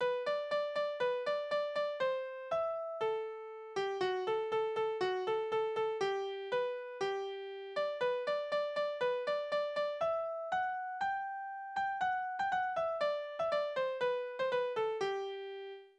Kinderlieder
Tonart: G-Dur
Taktart: 4/8
Tonumfang: Oktave
Besetzung: vokal